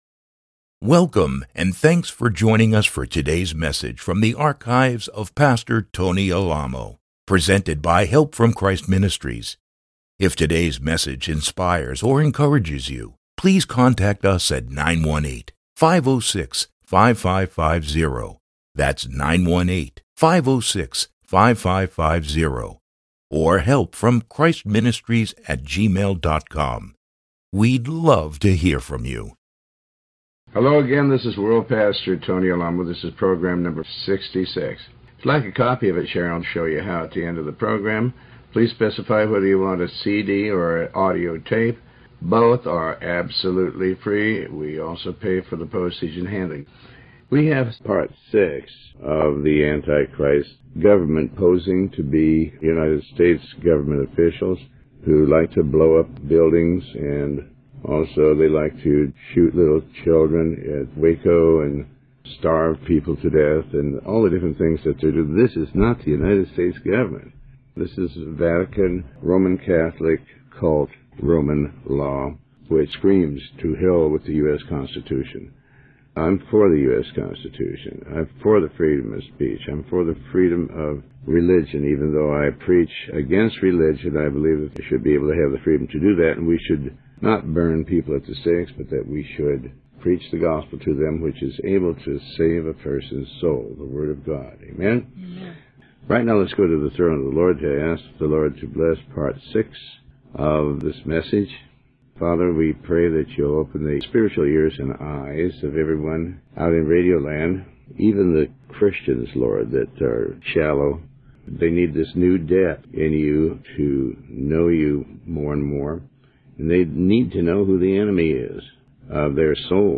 Sermon 66B